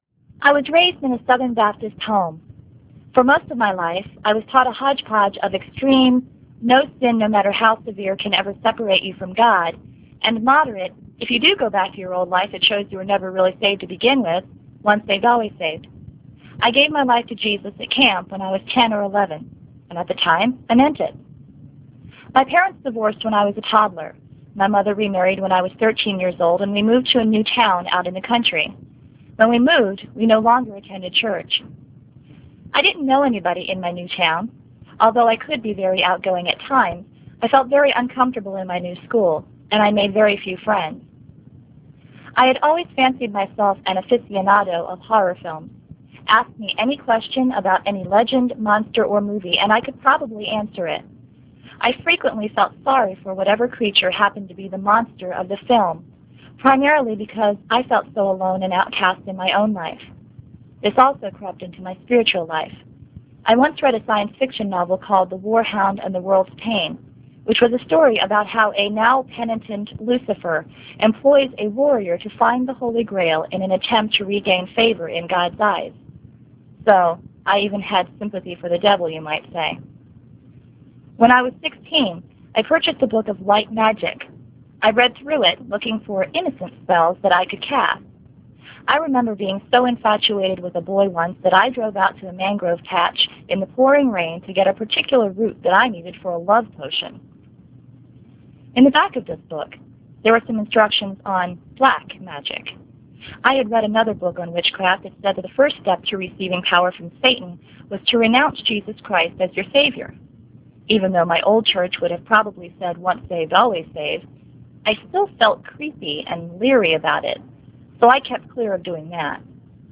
a Southern Baptist Salvation Testimony